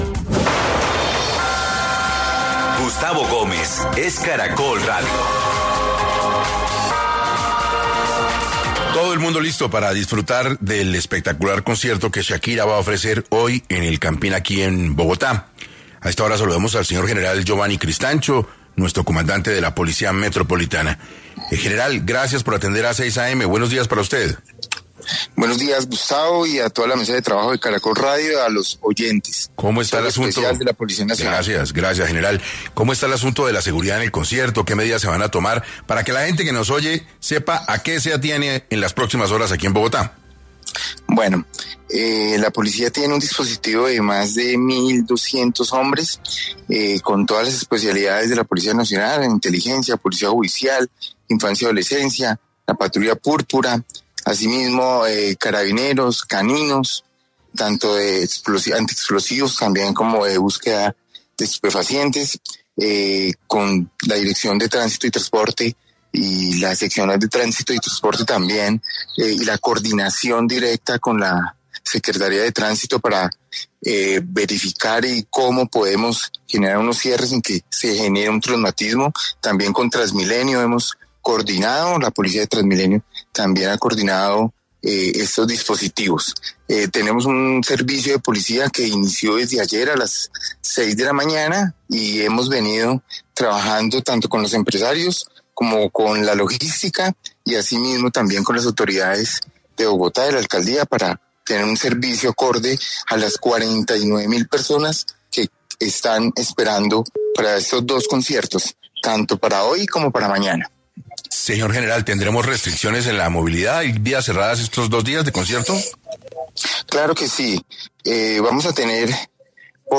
En 6AM de Caracol Radio el general Giovanni Cristancho, comandante de la Policía Metropolitana de Bogotá, entregó algunas indicaciones en materia de seguridad para los asistentes del tan esperado concierto de Shakira en el Estadio Nemesio Camacho del Campín.